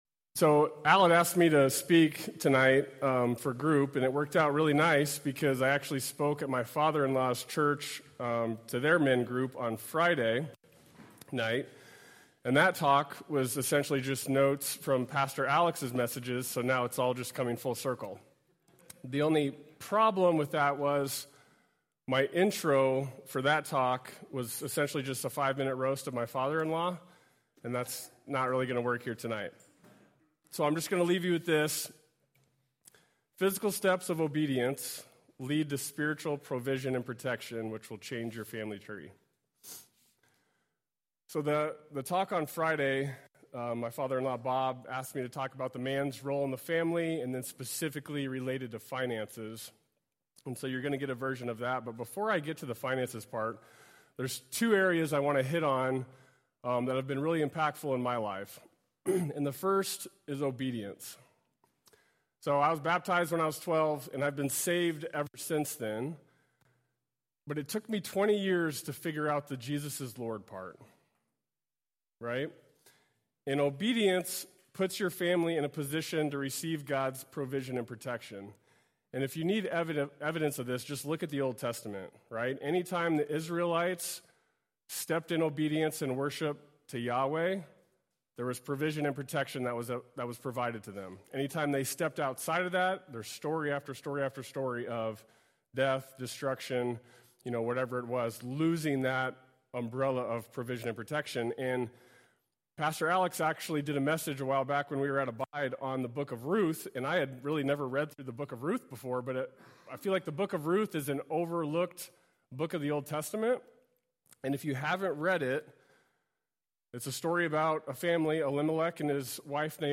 speaks on the Lord’s provision for the family at Revival’s Sons of Thunder men’s group.